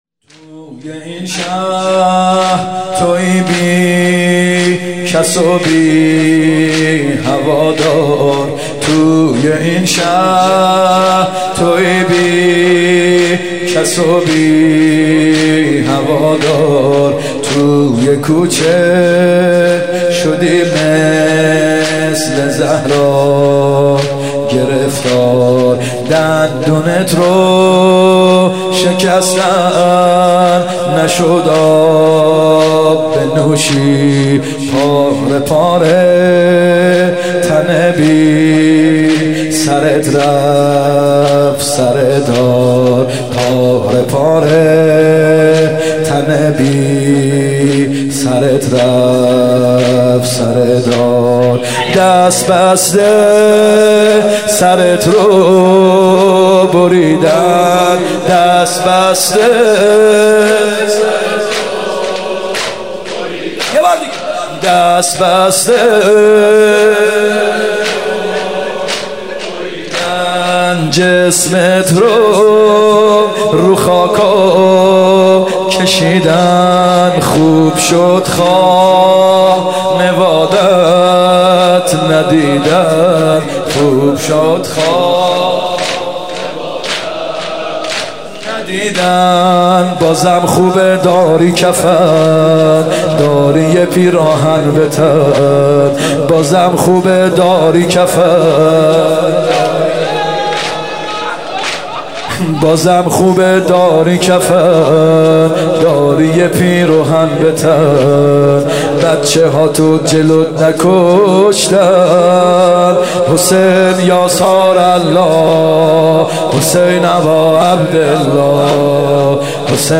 محرم 95(هیات یا مهدی عج)